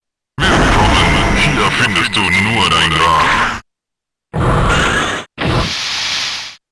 DIÁLOGOS